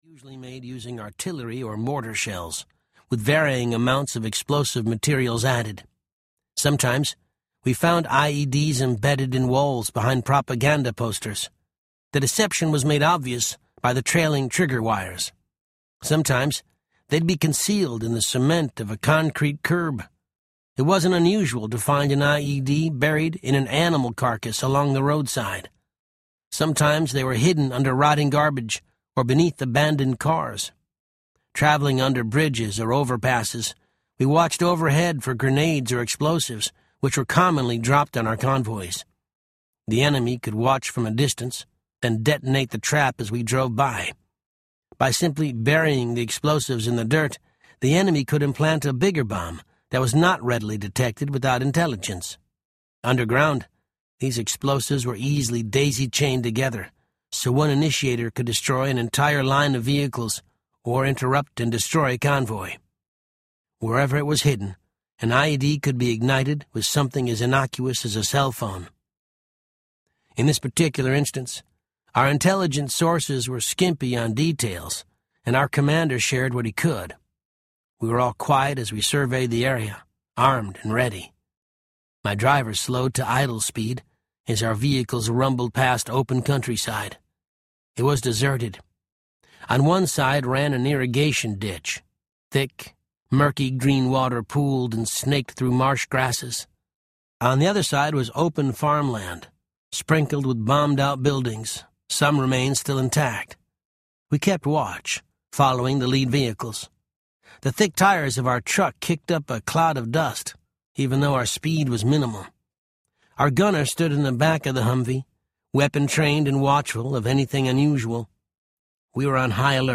Steel Will Audiobook
Narrator
10 Hrs. – Unabridged